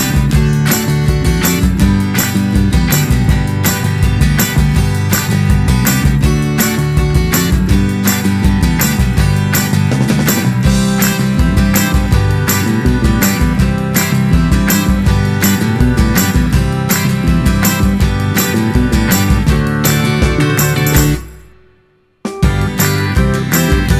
Minus Piano Pop (1960s) 3:16 Buy £1.50